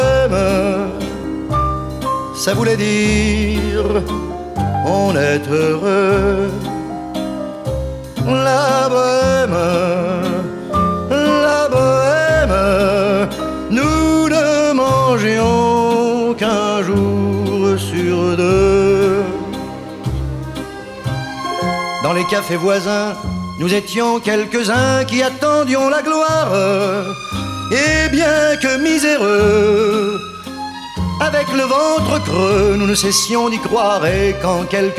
Жанр: Поп
# French Pop